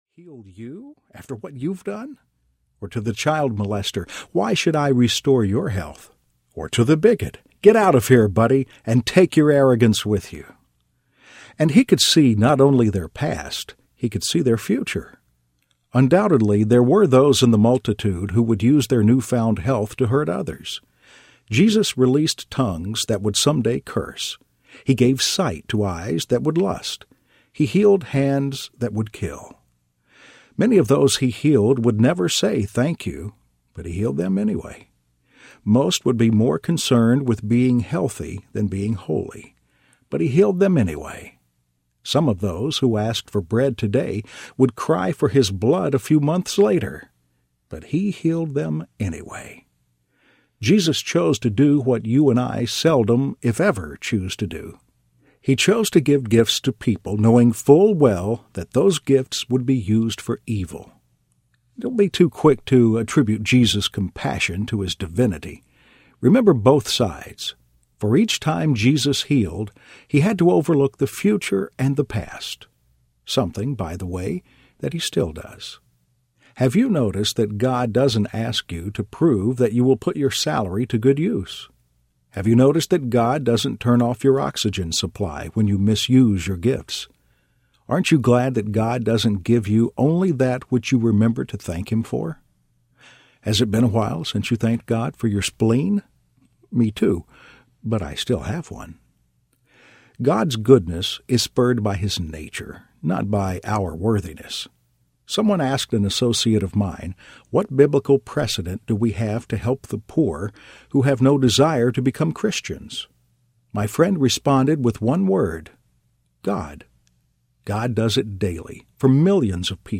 In The Eye of the Storm Audiobook
Narrator
4.7 Hrs. – Unabridged